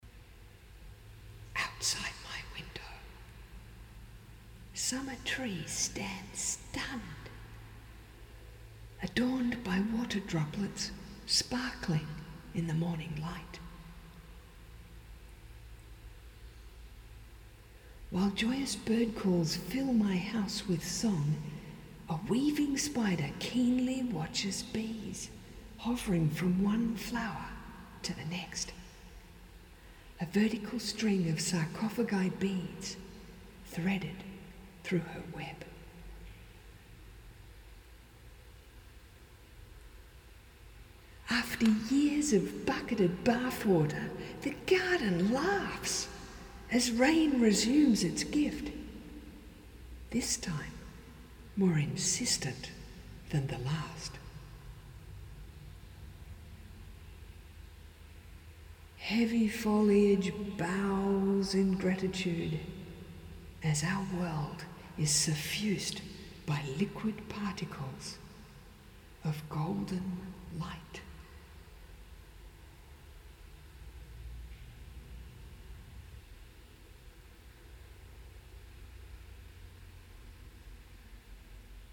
spoken voice